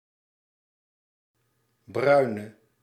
Ääntäminen
Ääntäminen Tuntematon aksentti: IPA: /ˈbrœy̯.nə/ Haettu sana löytyi näillä lähdekielillä: hollanti Käännöksiä ei löytynyt valitulle kohdekielelle.